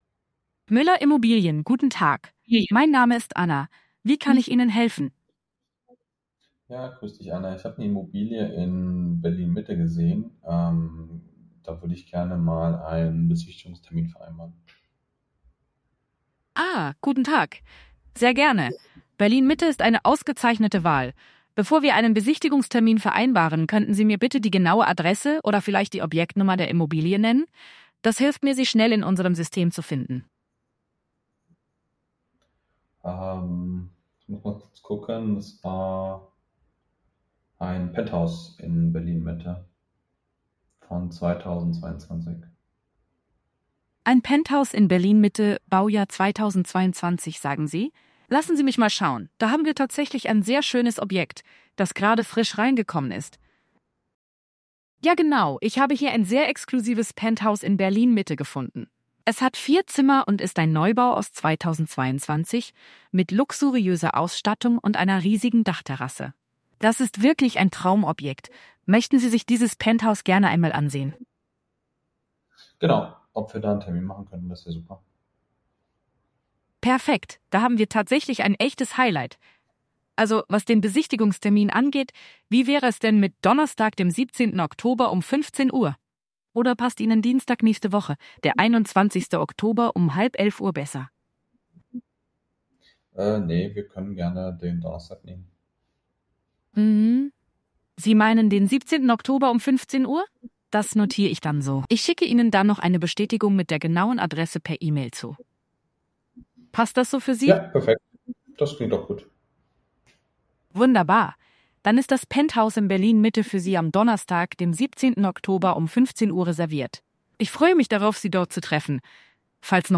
Live Agent Calls
Hören Sie unsere KI-Agenten in Aktion
Diese Aufnahmen zeigen die natürliche Konversationsfähigkeit und professionelle Kommunikation unserer Technologie.